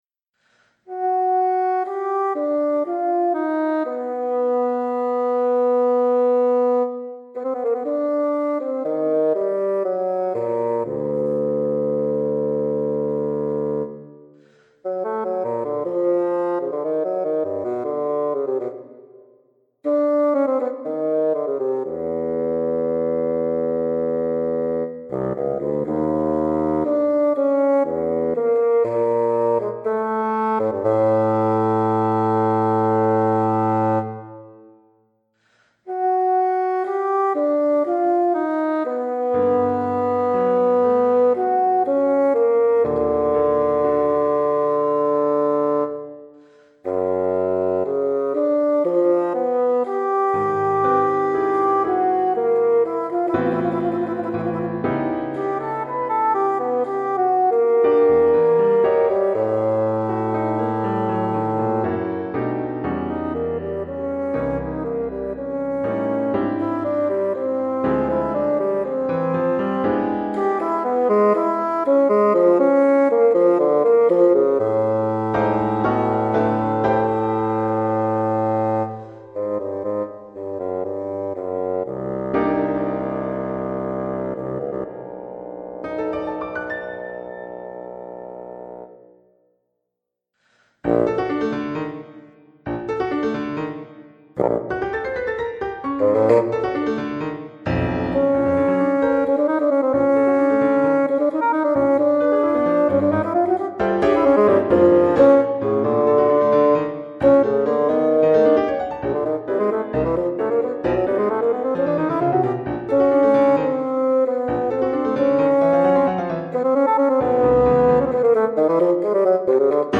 Categories » Bassoon
midi version